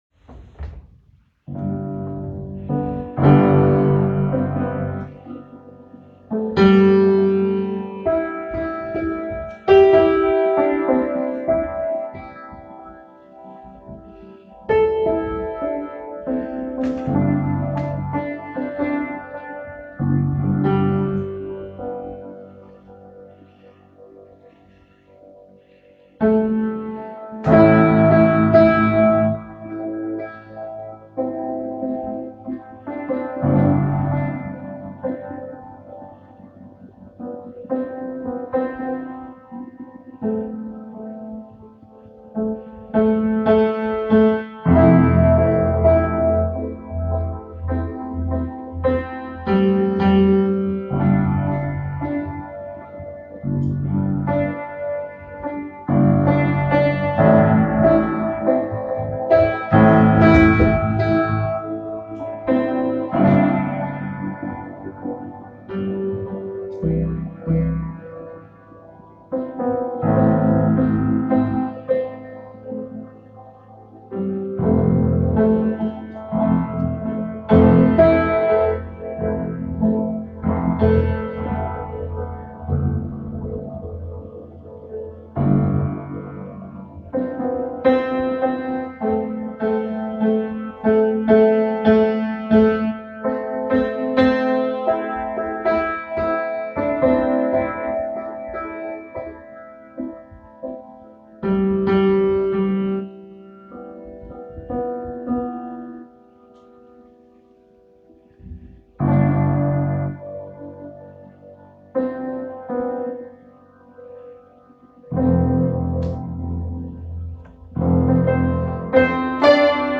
PIANO ABC (33)